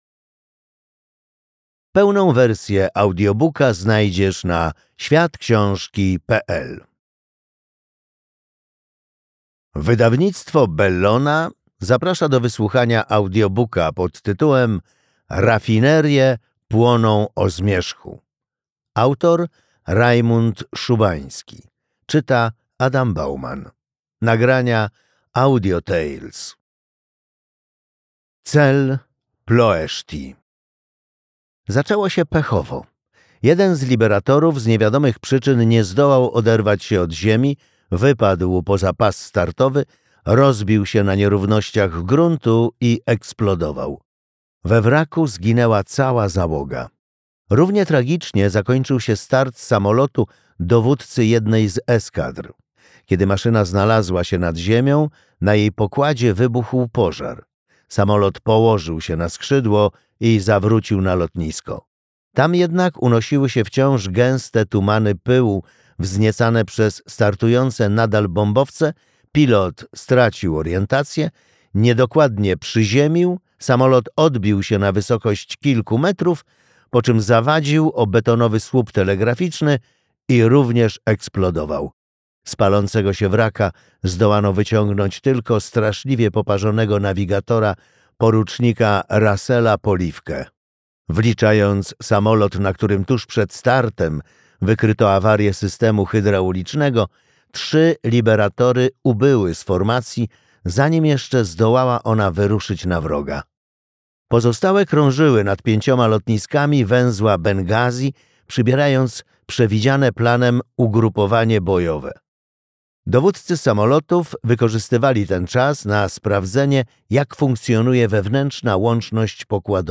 Rafinerie płoną o zmierzchu - Rajmund Szubański - audiobook